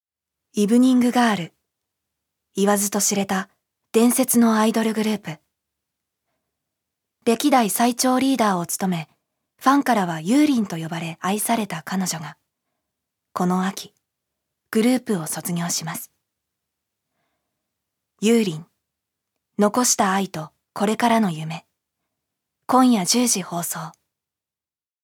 預かり：女性
ナレーション２